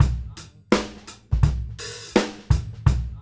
GROOVE 200JL.wav